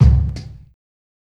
KICK_GETOUT.wav